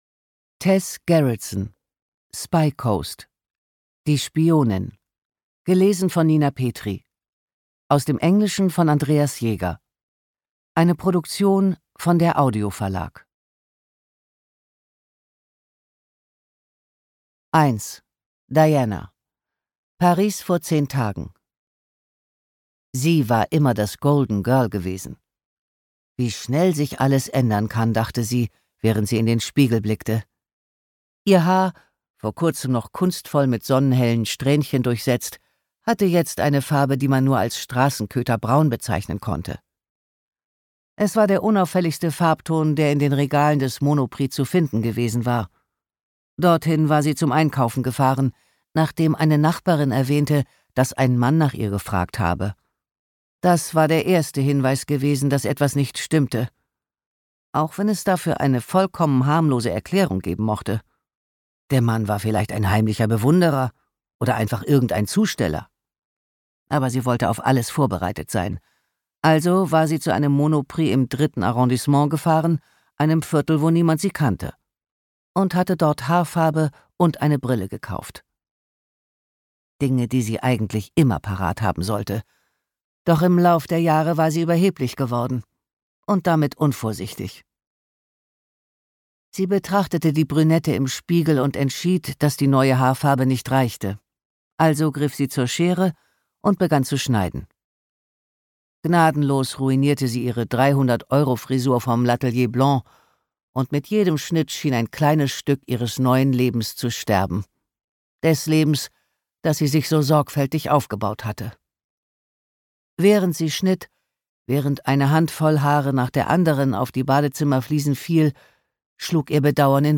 Ungekürzte Lesung mit Nina Petri (2 mp3-CDs)
Nina Petri (Sprecher)